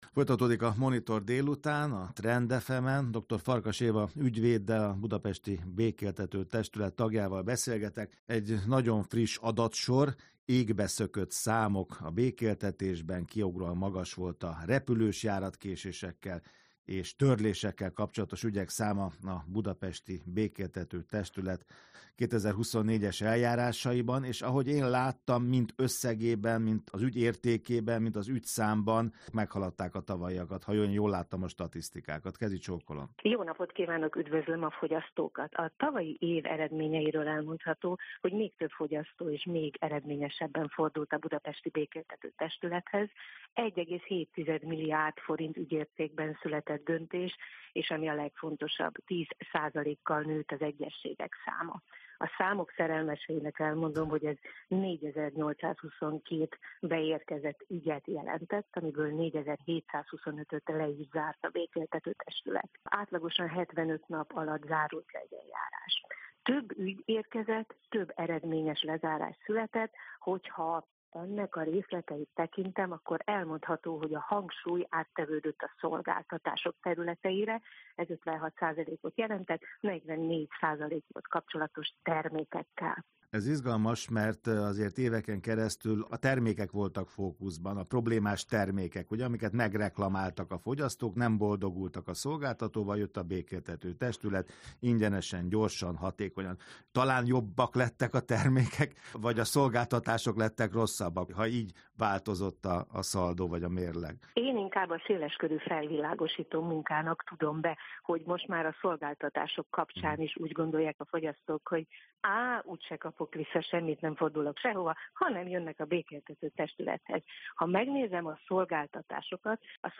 Rádióinterjú a Budapesti Békéltető Testület 2024-es eredményeiről